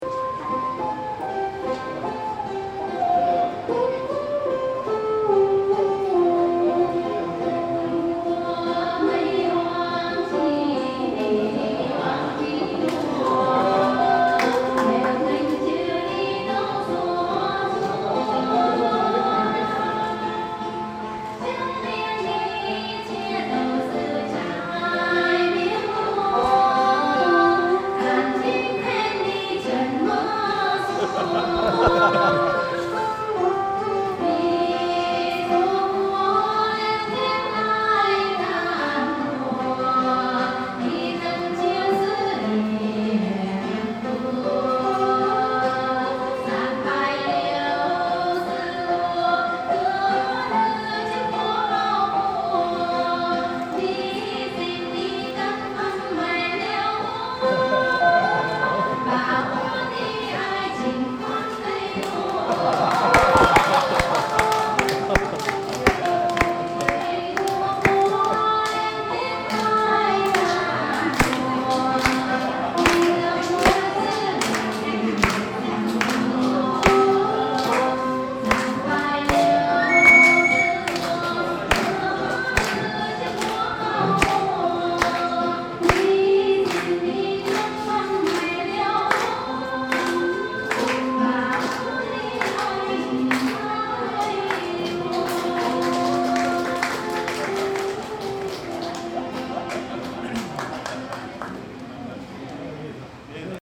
13/04/2014 13:30 Dans le temple de la litterature, à Hanoi, il y a un vieux bâtiment avec une grosse cloche.
Dans la salle d'à côté, un groupe de musiciennes fait un show. Derrière moi j'entends un guide vietnamien dire à deux touristes américains : " Il est 13h30, vous préférez le repas ou la prison ? Parce que la prison est bien, et c'est juste à côté"